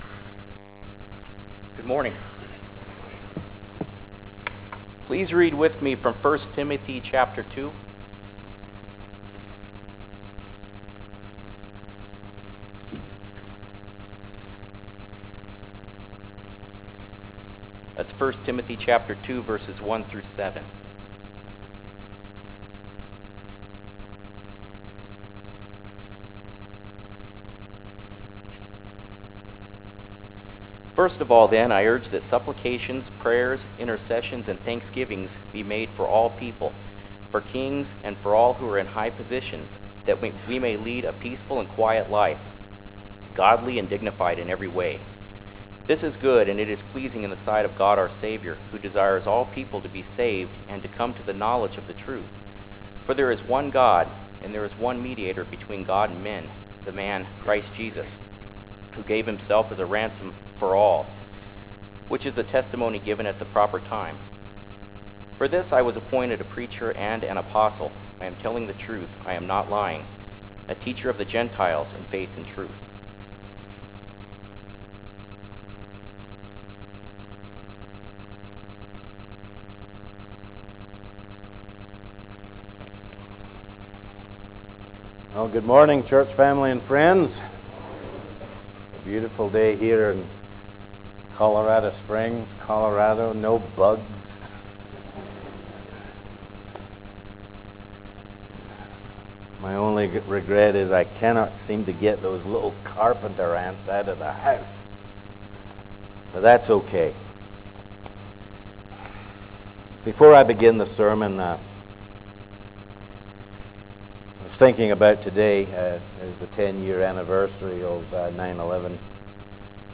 from → Classes / Sermons / Readings, Sermon